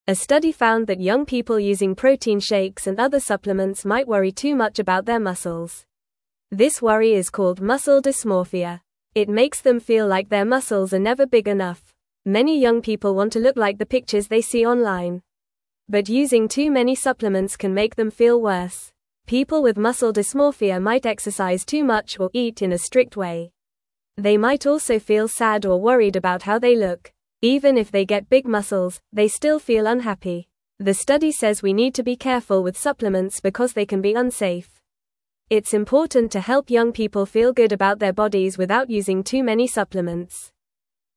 Fast
English-Newsroom-Beginner-FAST-Reading-Worrying-About-Muscles-Can-Make-You-Unhappy.mp3